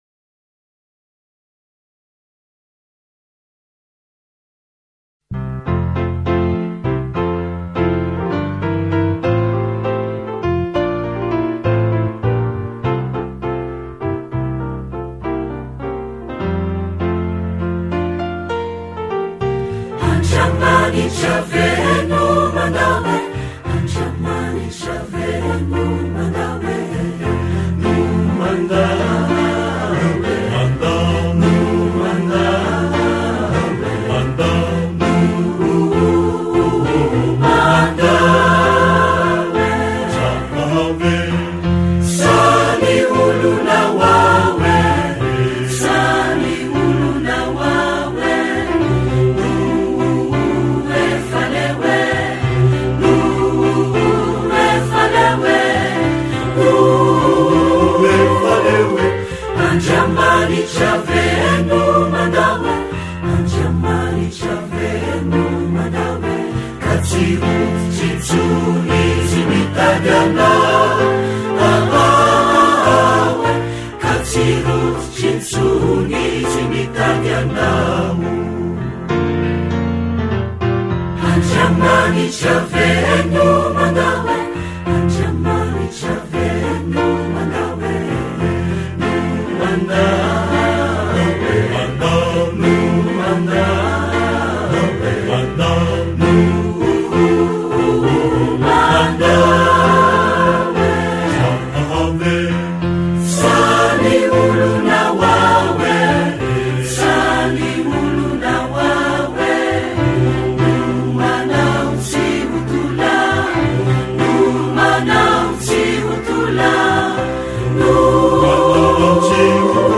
Andriamanitra_ve_no_mandao_-_-Chorale_anjomara_anosivavaka.mp3